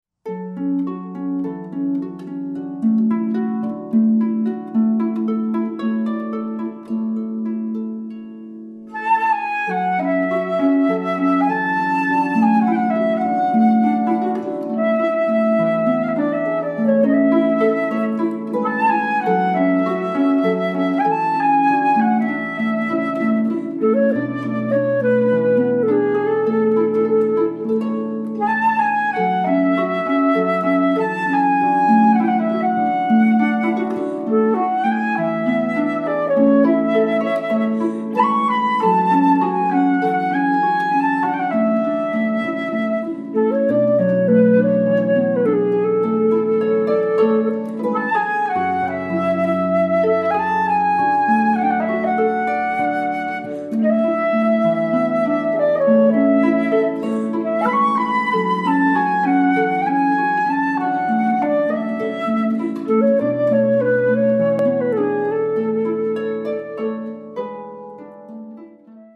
saxophone
Jazz/Pop